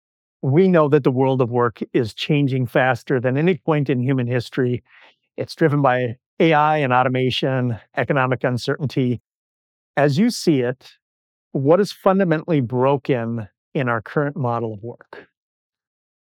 The process preserves the original voice, leaving a natural, enhanced version of your recording crisper and easier to listen to.
Cleaned-Restored-Audio.wav